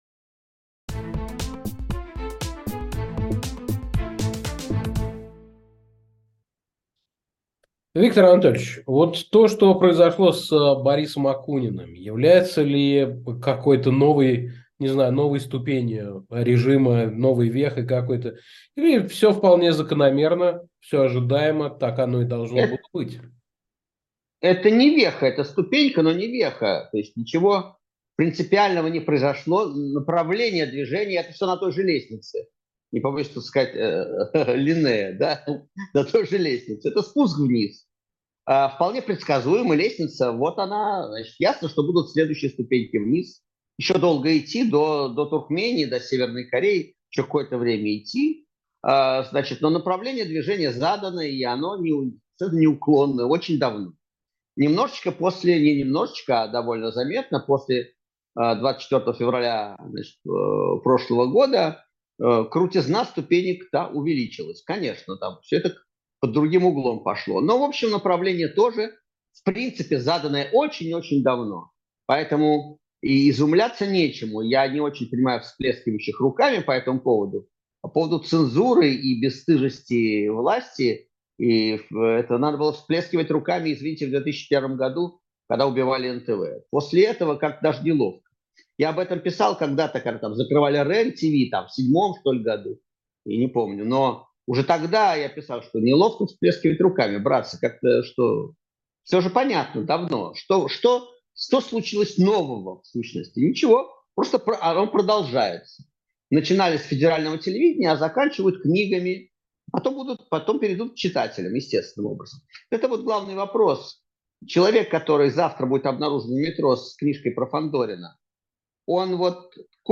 Интервью с Виктором Шендеровичем - ЭХО
Эфир ведёт Александр Плющев